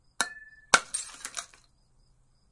描述：锤子打在啤酒瓶上，1下然后轻砸，水龙头
Tag: 锤打击瓶 一打 光粉碎